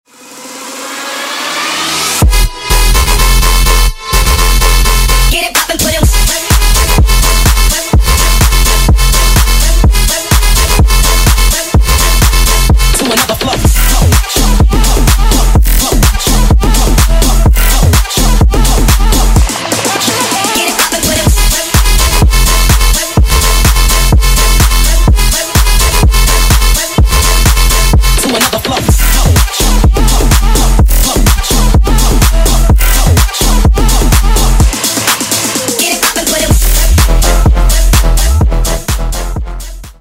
DnB Drum And Bass